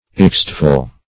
Search Result for " exciteful" : The Collaborative International Dictionary of English v.0.48: Exciteful \Ex*cite"ful\, n. Full of exciting qualities; as, an exciteful story; exciteful players.
exciteful.mp3